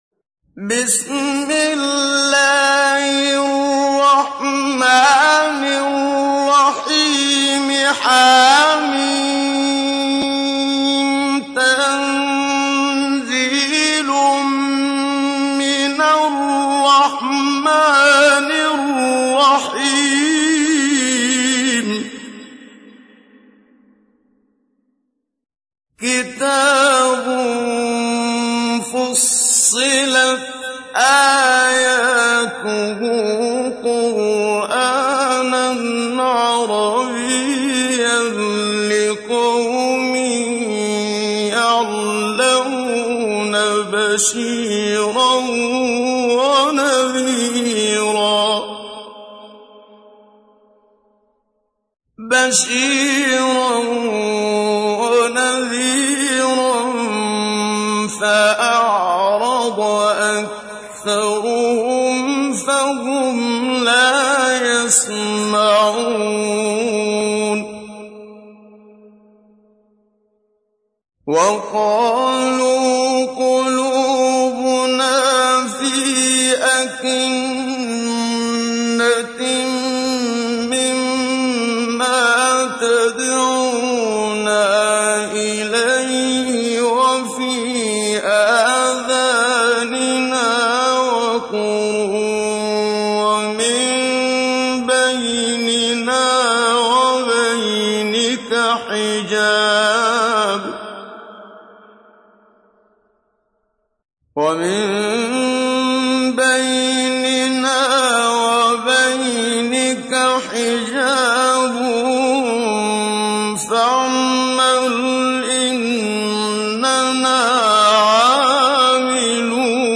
تحميل : 41. سورة فصلت / القارئ محمد صديق المنشاوي / القرآن الكريم / موقع يا حسين